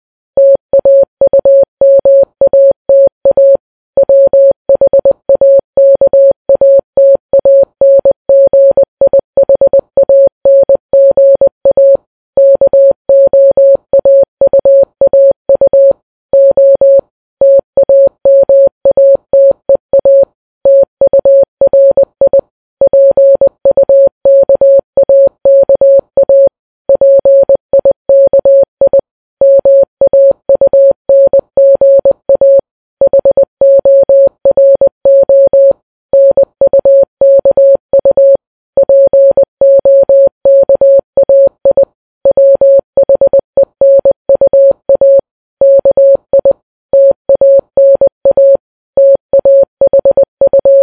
Morse challenge